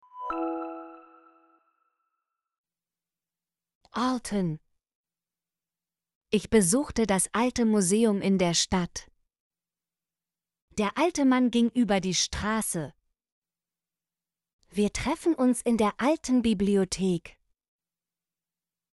alten - Example Sentences & Pronunciation, German Frequency List